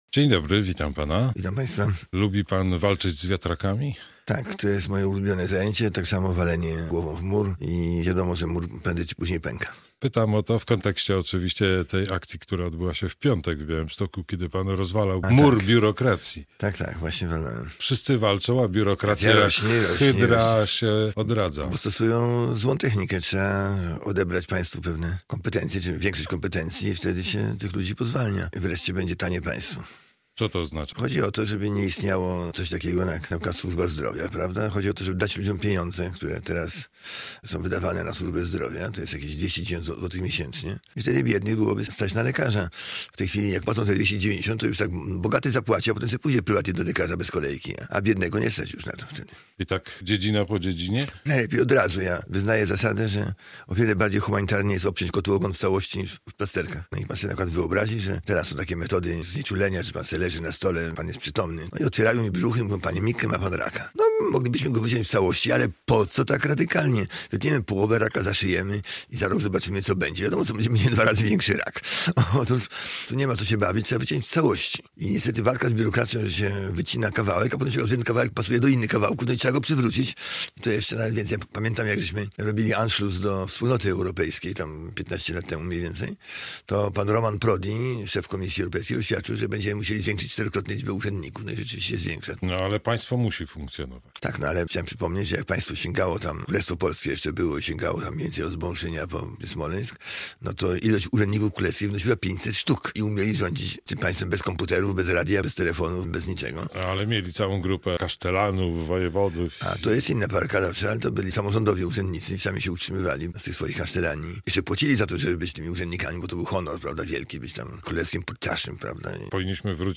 Radio Białystok | Gość | Janusz Korwin-Mikke - lider Partii Wolność